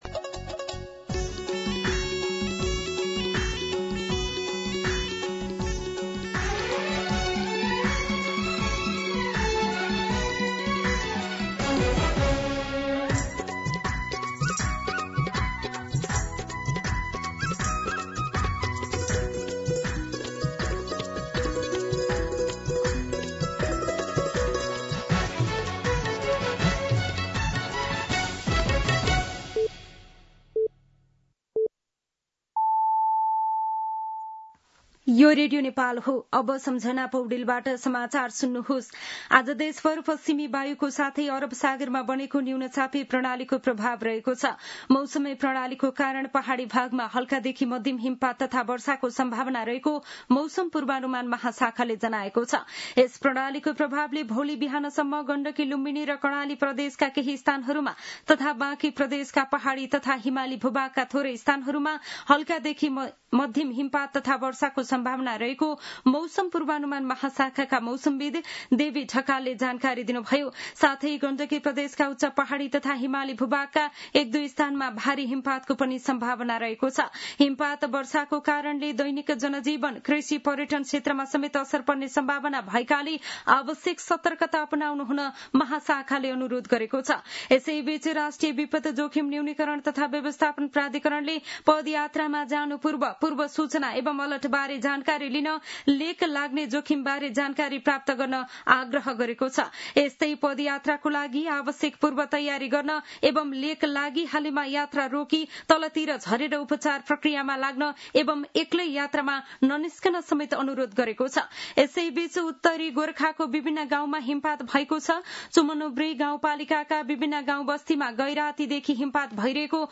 मध्यान्ह १२ बजेको नेपाली समाचार : ११ कार्तिक , २०८२
12pm-News-4.mp3